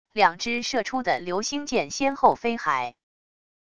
两支射出的流星箭先后飞还wav下载